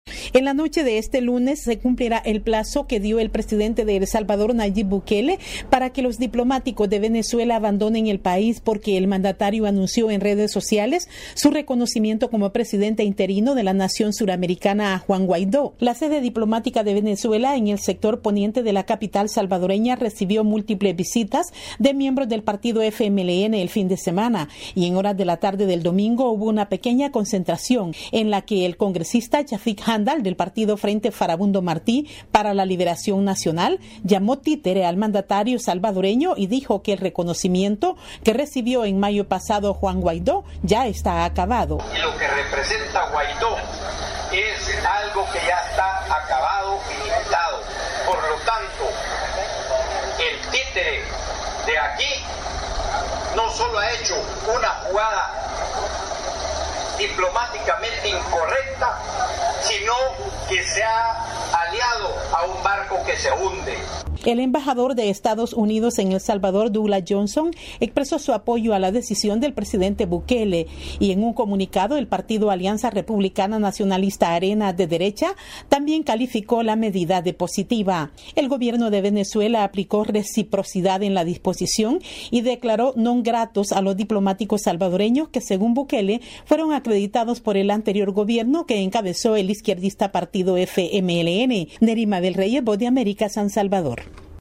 VOA: Informe desde El Salvador